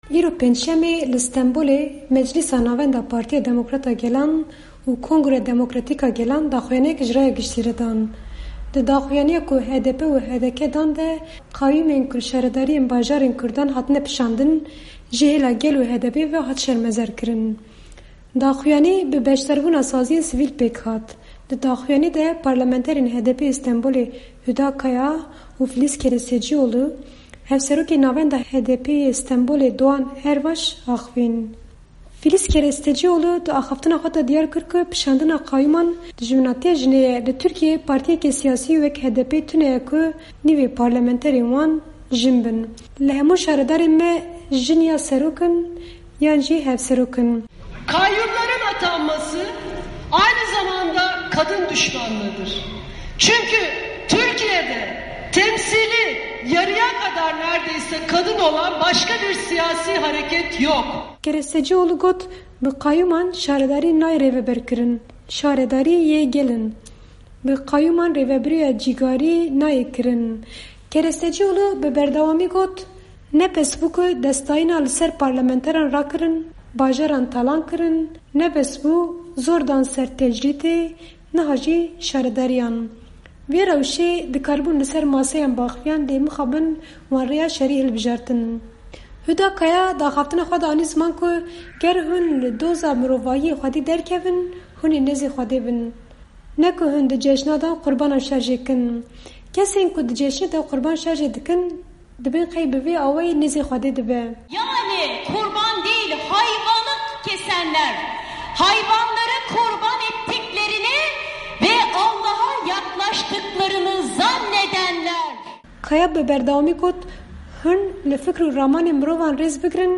Daxuyanî bi beşdarbûna sazyên sivîl pêk hat.